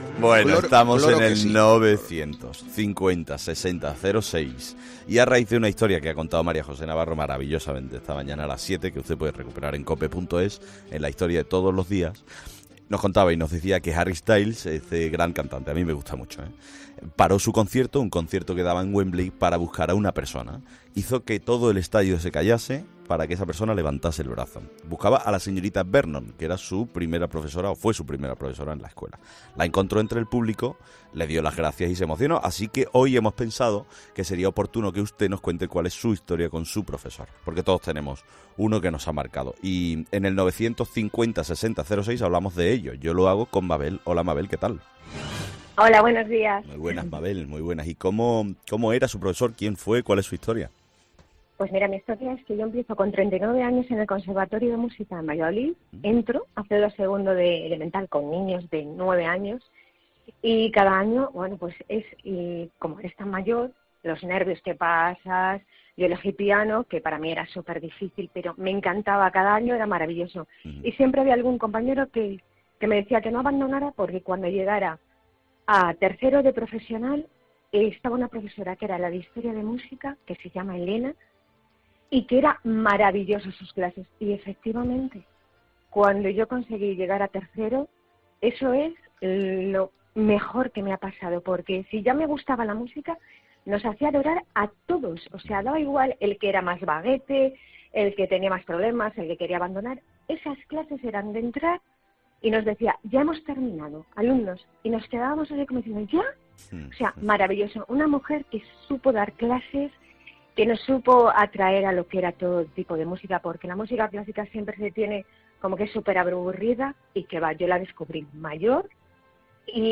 Por eso, los oyentes compartieron con nosotros anécdotas con sus profesores.